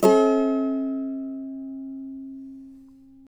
CAVA A#MJ  U.wav